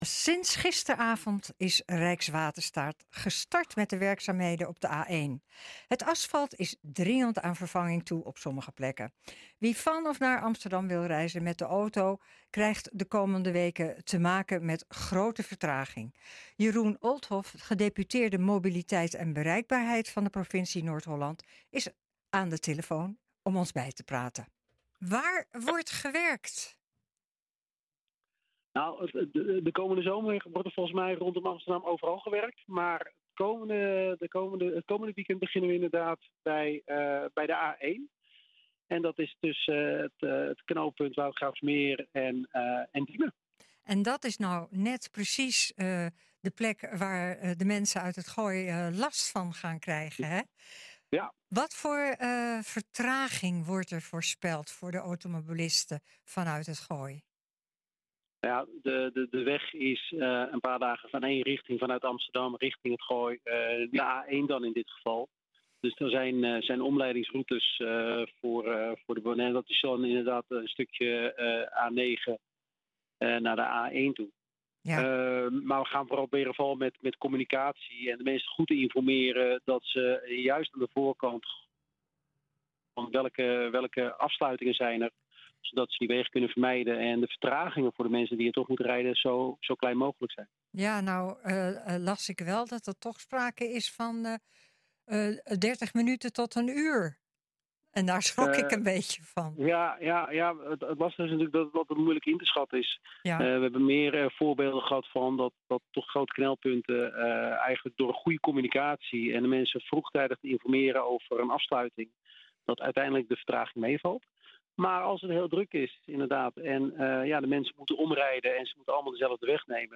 Wie van of naar Amsterdam wil reizen met de auto krijgt de komende weken te maken met grote vertraging. Jeroen Olthof, gedeputeerde mobiliteit en bereikbaarheid van de Provincie Noord Holland is aan de telefoon om ons bij te praten.